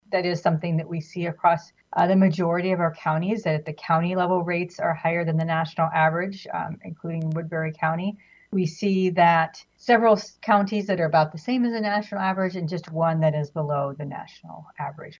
CANCER RATES IN WOODBURY COUNTY WERE THE MAIN TOPIC OF DISCUSSION AT A HEALTH FORUM AT WESTERN IOWA TECH THURSDAY MORNING.